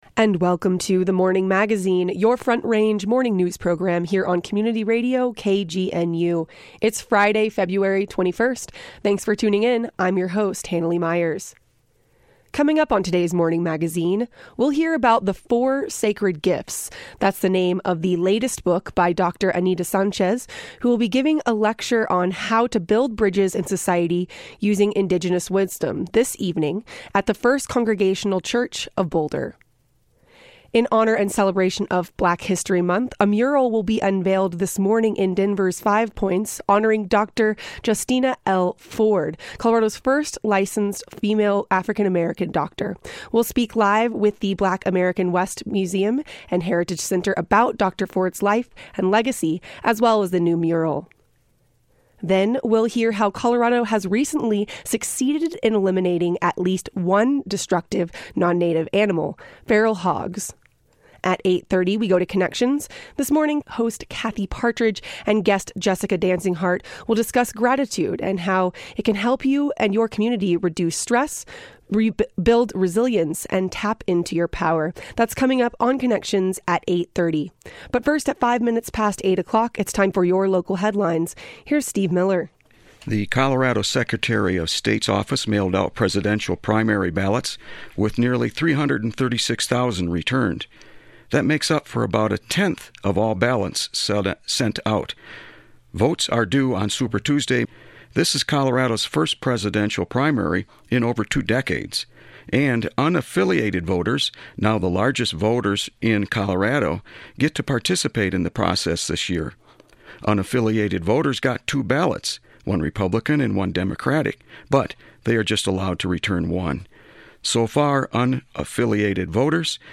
We close the program with a report on how Colorado has recently succeeded in eliminating feral hogs.